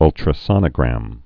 (ŭltrə-sŏnə-grăm, -sōnə-)